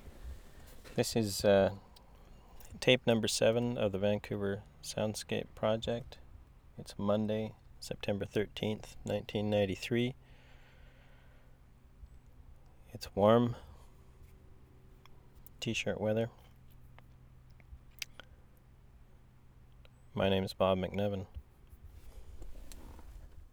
WORLD SOUNDSCAPE PROJECT TAPE LIBRARY
NORTH SHORE , SEPT 13, 1993
1. tape ID